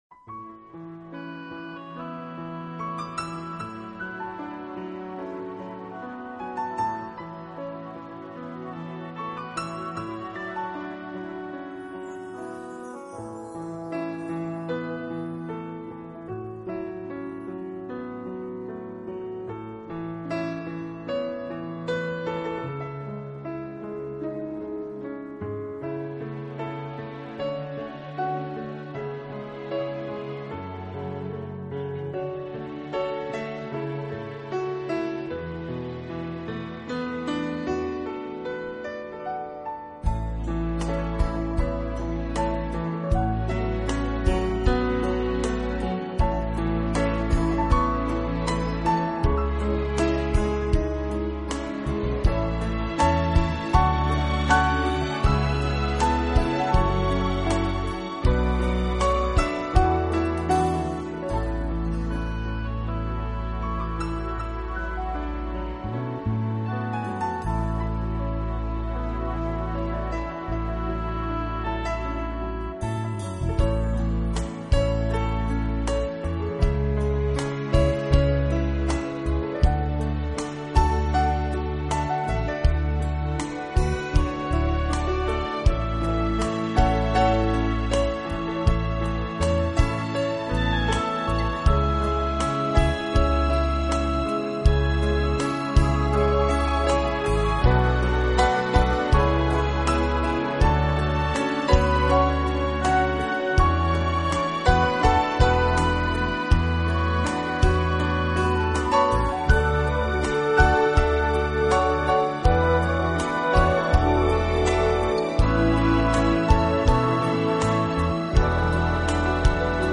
【名品钢琴】
音乐类型：CD古典跨界音乐